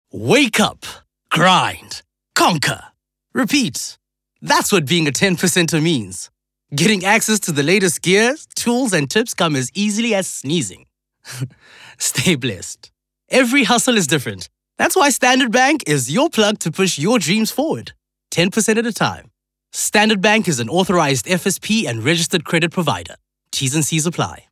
Vocal Styles:
energetic, playful, upbeat, youthful
I am an articulate voice artist who excels with the use of natural mid-range tones. I would best describe my voice as clear, vibrant and pleasant.
My demo reels